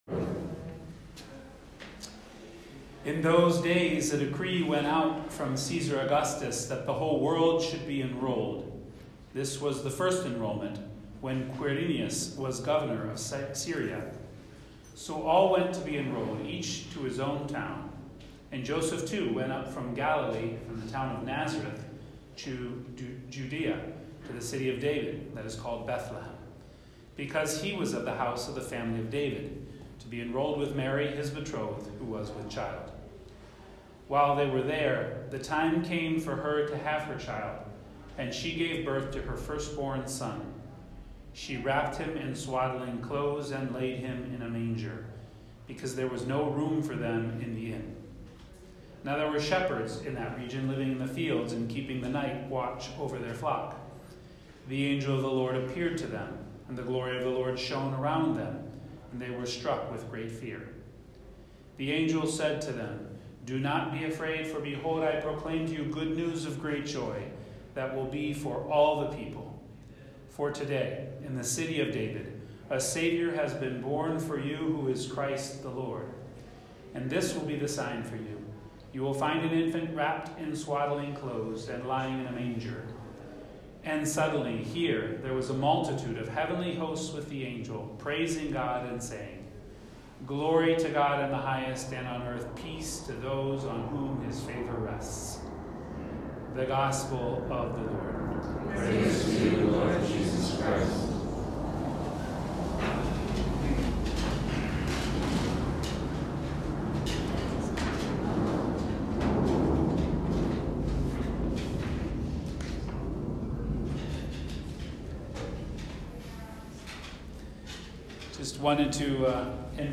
Homilies from the Holy Land: The Joy of Victory - RC NY Tri-State
Day 5: Bethlehem From April 20-27